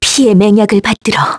Lewsia_B-Vox_Skill5-1_kr.wav